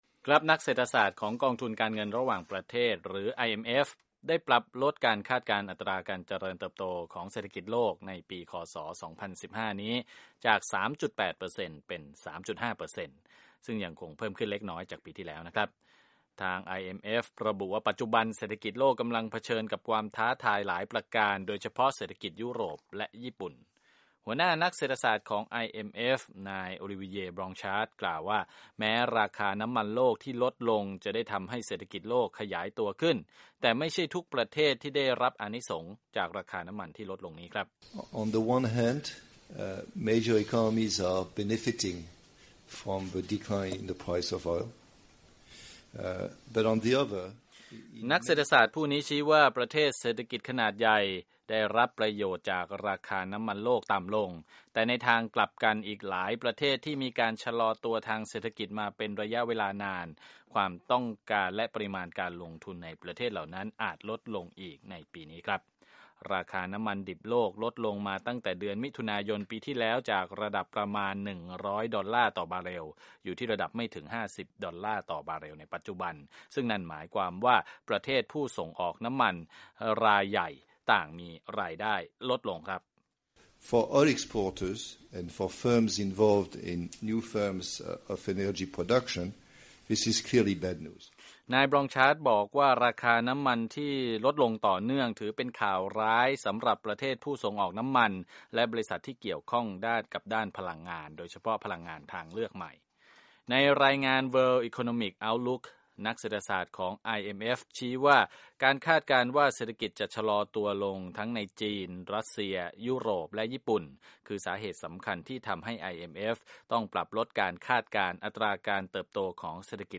รายงานจากห้องข่าว VOA